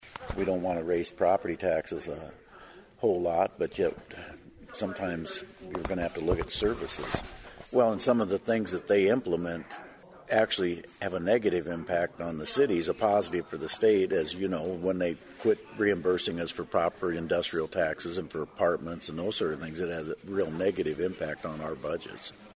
MEMBERS OF THE CITY COUNCIL OF SIOUX CITY AND VARIOUS DEPARTMENT HEADS MET WITH LOCAL IOWA STATE LAWMAKERS FRIDAY MORNING TO DISCUSS ISSUES AND PRIORITIES FOR THE UPCOMING STATE LEGISLATIVE SESSION.
MAYOR BOB SCOTT SAYS PROPERTY VALUES DIFFER ACROSS THE STATE, AND SUGGESTS THAT WHAT STATE LAWMAKERS PASSED FOR PROPERTY TAX RELIEF DOESN’T TREAT ALL CITIES EQUALLY: